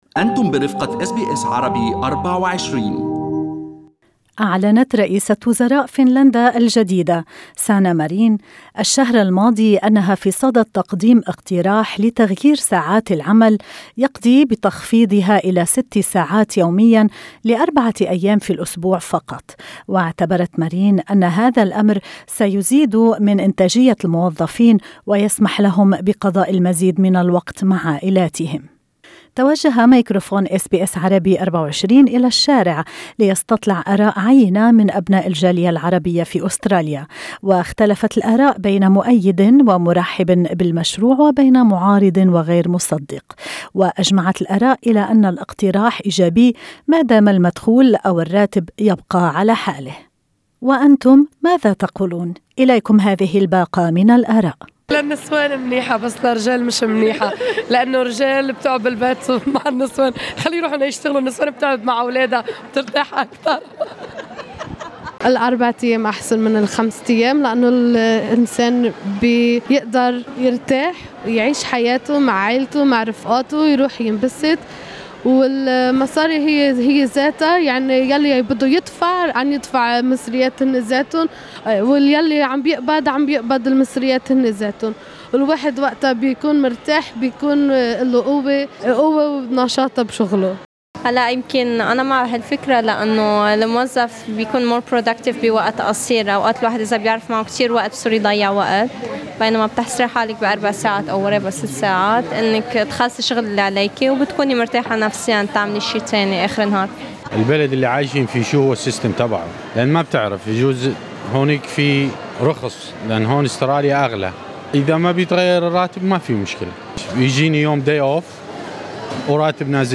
توجه ميكروفون اس بي اس عربي 24 الى الشارع، في منطقة سيدني الغربية، ليستطلع آراء عينة من أبناء الجالية العربية، واختلفت الآراء بين مؤيد ومرحب من جهة ومعارض وغير مصدق من جهة أخرى.